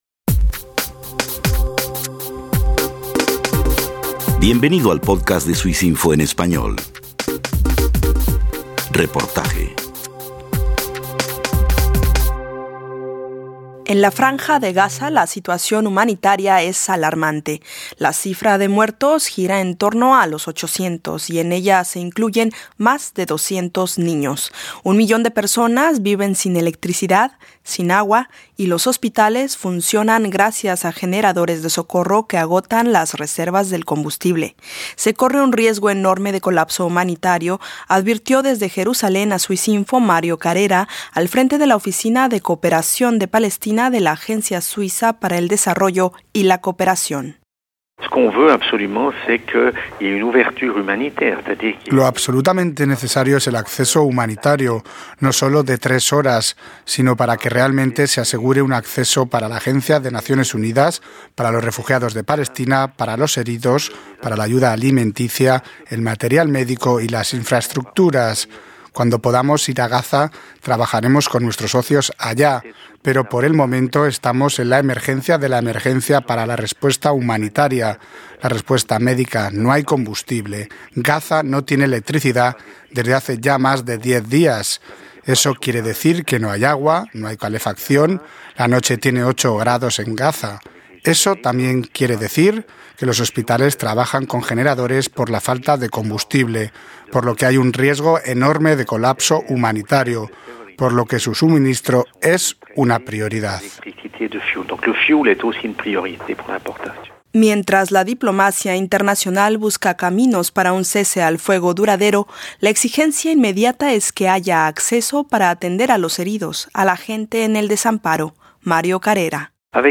Terres des Hommes, una de sus organizaciones asociadas en Gaza lamenta la muerte de más de 200 niños. El CICR, por su parte, observa violaciones al derecho internacional humanitario. Desde Berna, Suiza